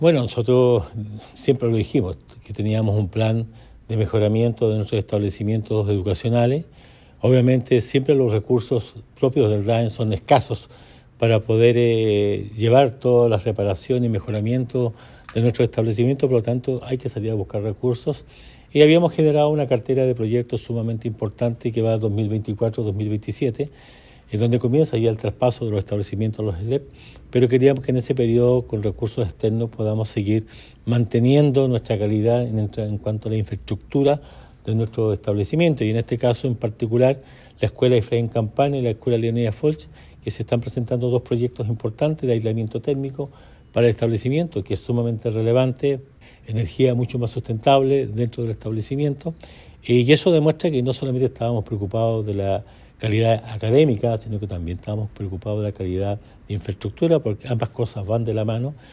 El Alcalde Emeterio Carrillo destacó la relevancia de este proyecto, señalando que las mejoras en la infraestructura escolar son esenciales para garantizar condiciones adecuadas para los estudiantes de la comuna.
11-noviembre-24-emeterio-carrillo-mejoramiento.mp3